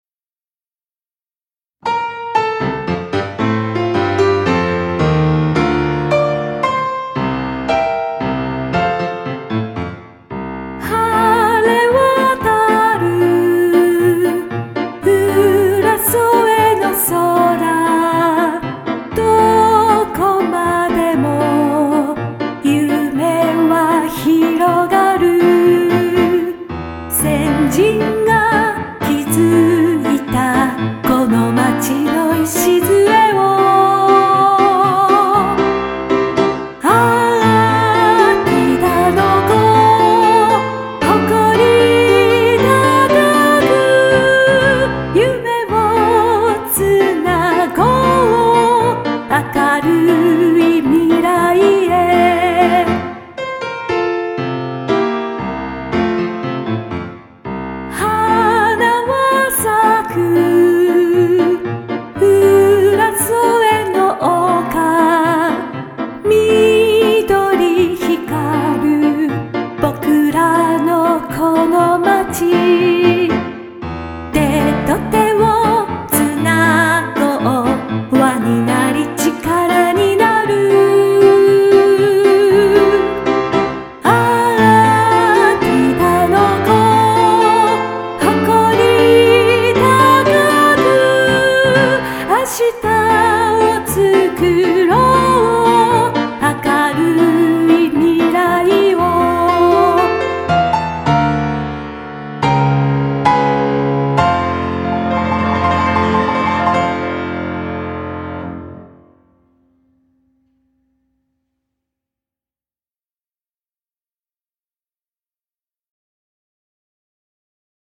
小さな子どもから大人に至るまで誰もが口ずさみやすく、覚えやすく、親しみやすい。